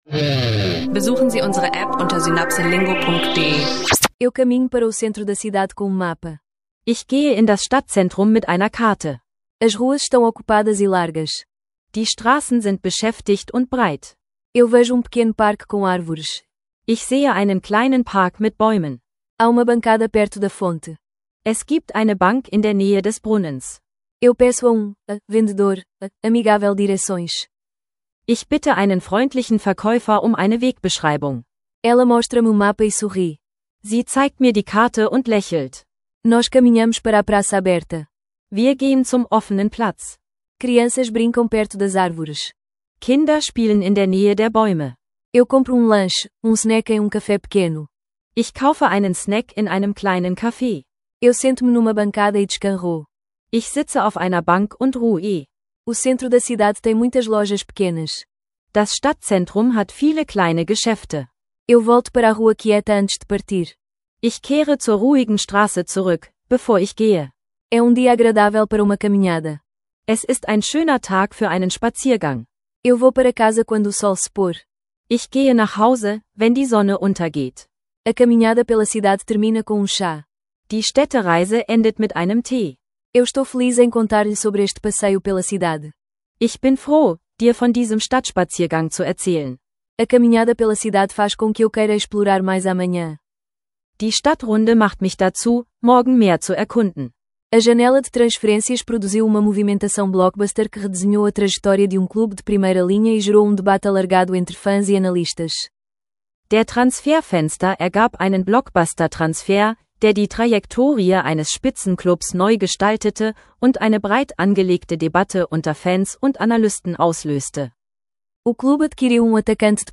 Dialoge, übe Vokabeln und setze das Gehörte direkt um.